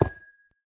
Minecraft Version Minecraft Version latest Latest Release | Latest Snapshot latest / assets / minecraft / sounds / block / amethyst / place1.ogg Compare With Compare With Latest Release | Latest Snapshot